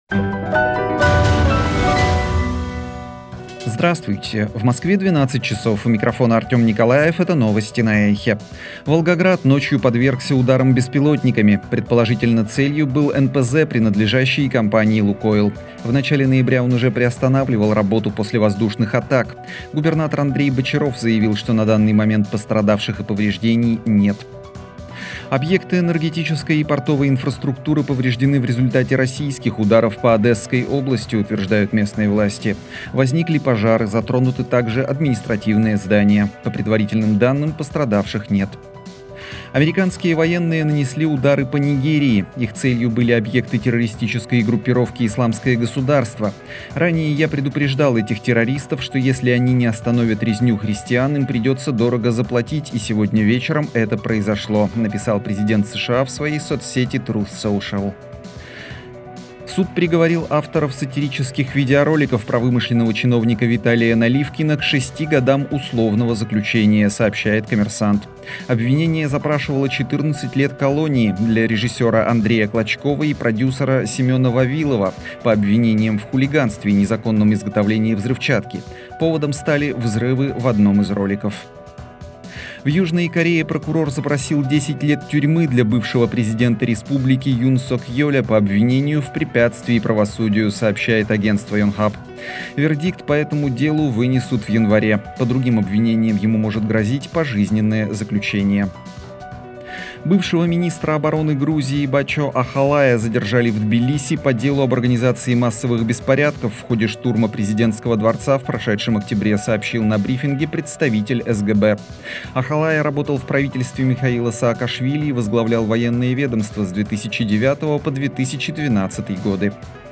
Новости 12:00